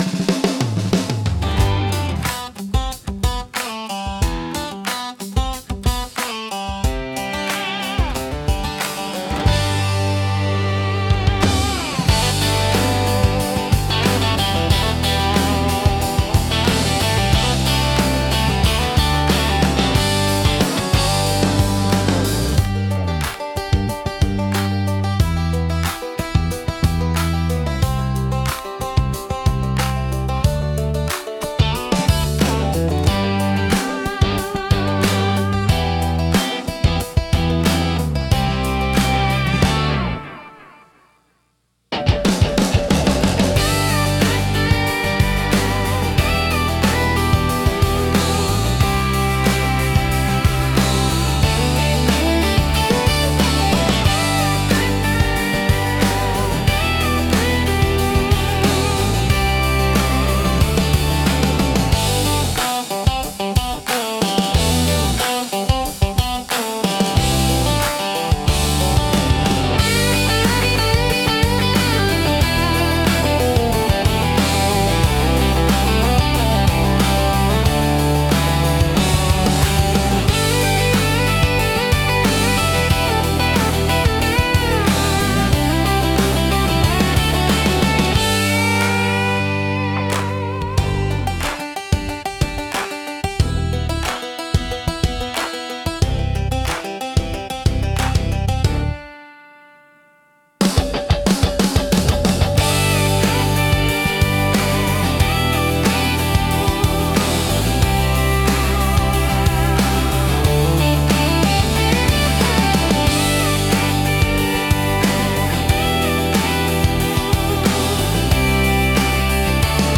聴く人に快適で穏やかな気持ちをもたらし、ナチュラルで親近感のある空気感を演出します。